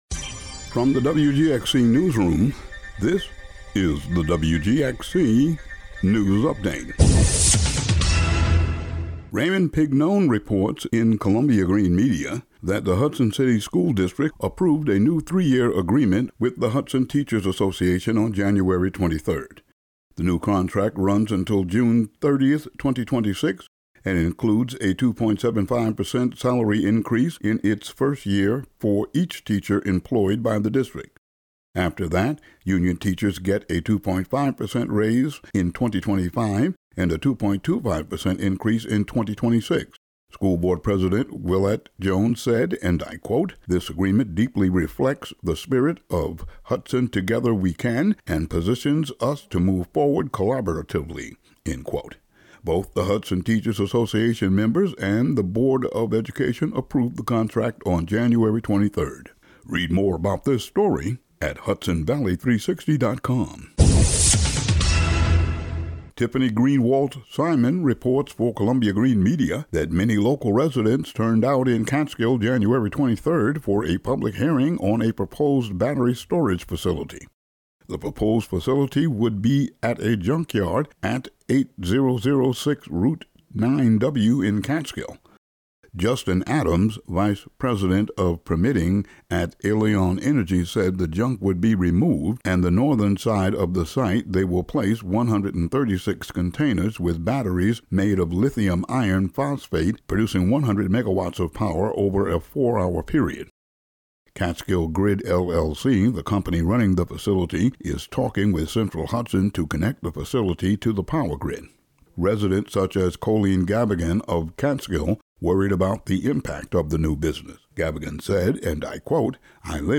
Today's audio daily news update.